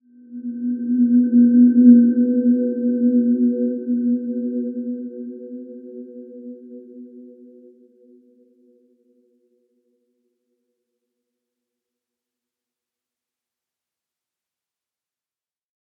Dreamy-Fifths-B3-p.wav